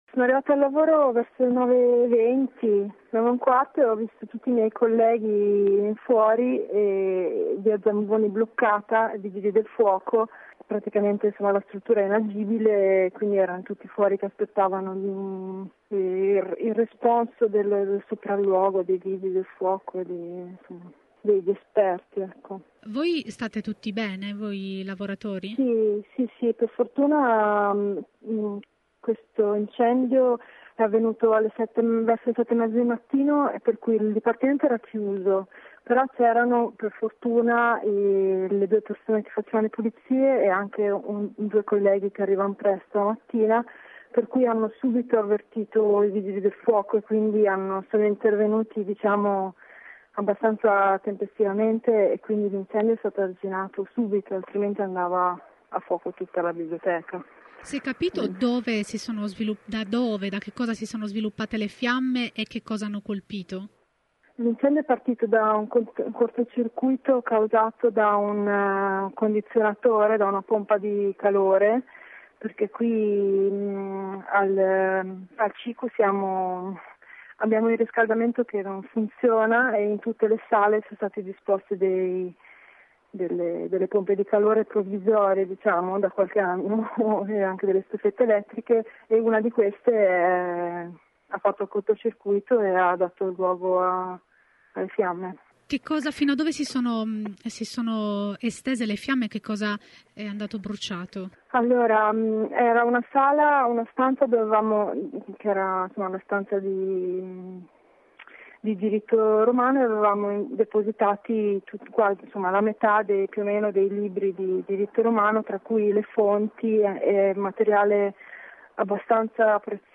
La testimonianza